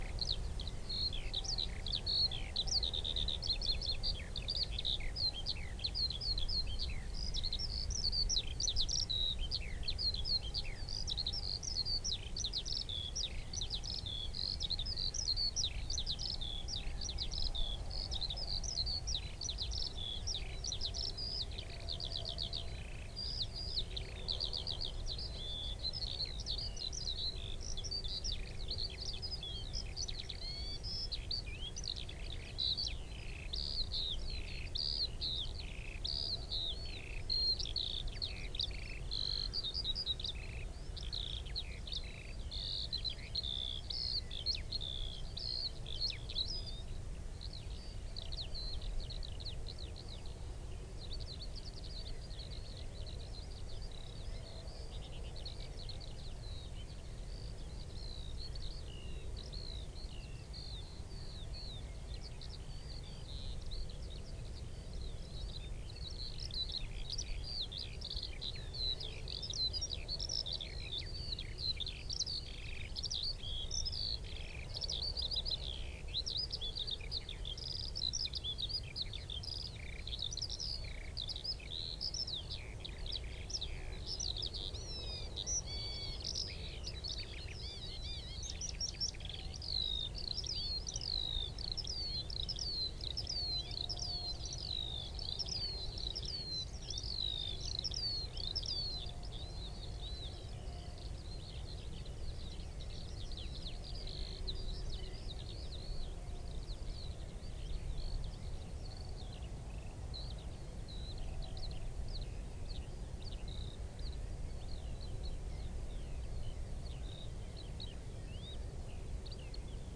Corvus corone
Sylvia communis
Emberiza citrinella
Turdus merula
Alauda arvensis